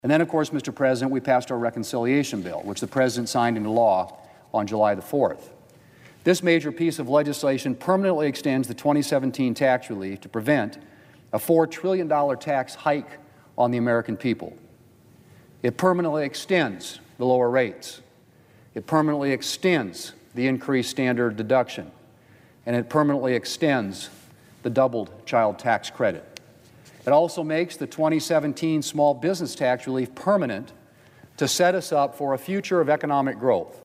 WASHINGTON, D.C.(HubCityRadio)- On Wednesday, Senate Majority Leader John Thune was on the floor of the U.S. Senate to reflect on the first six months of the session.